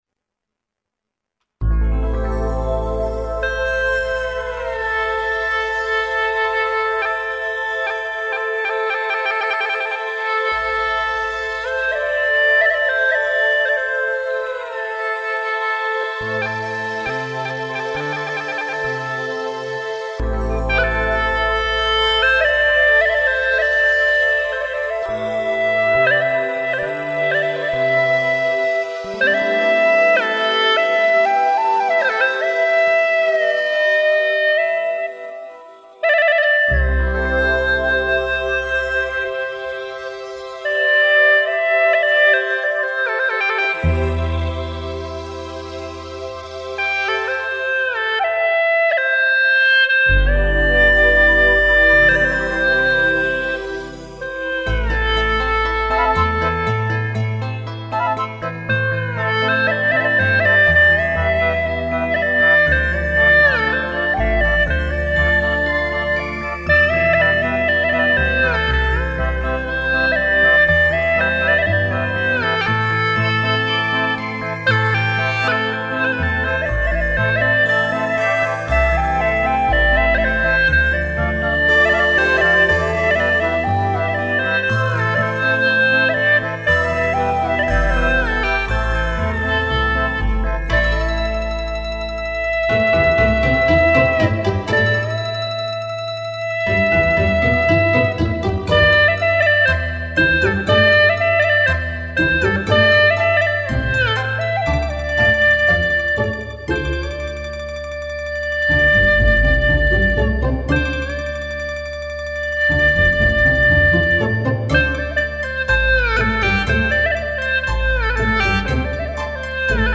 调式 : C 曲类 : 独奏